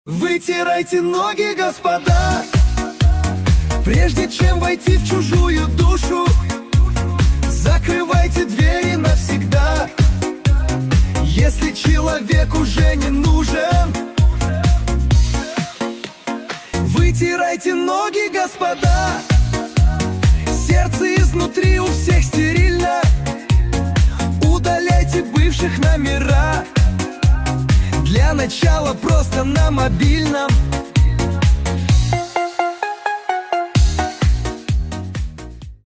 нейросеть
поп
душевные